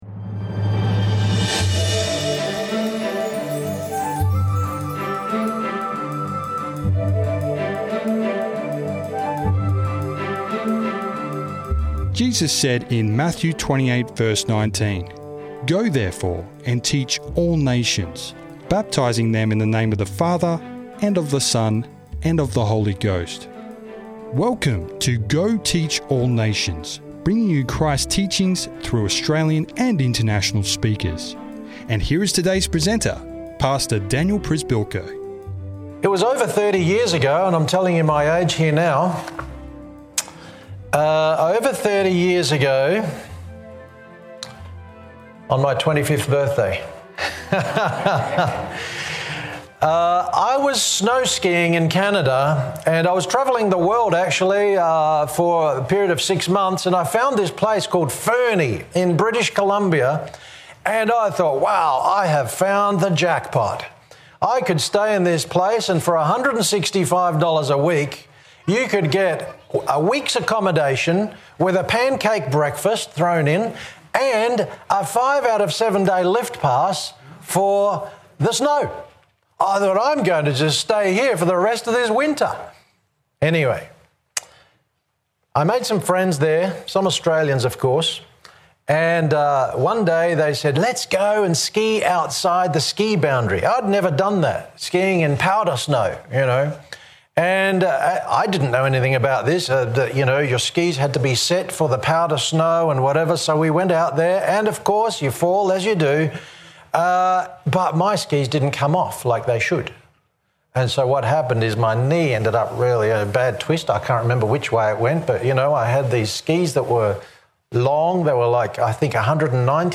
This message was made available by the Waitara Seventh-day Adventist church.